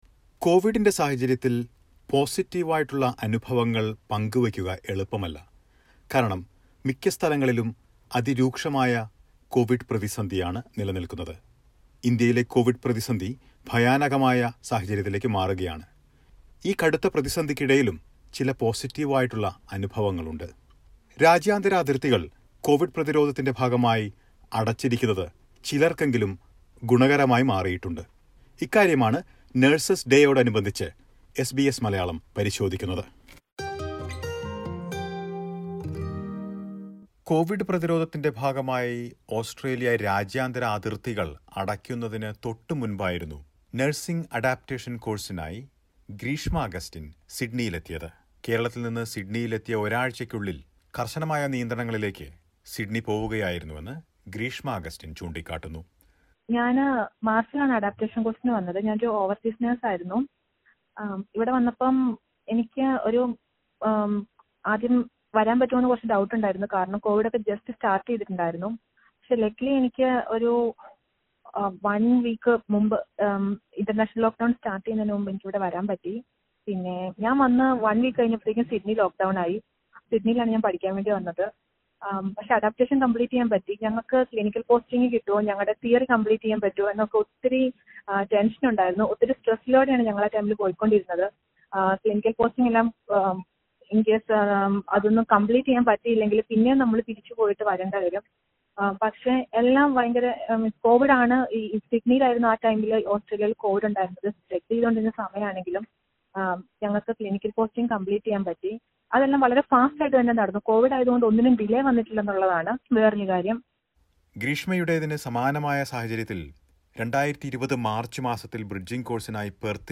Many nurses who came to Australia for three-month-long bridging course ended up getting a job. Listen to a report.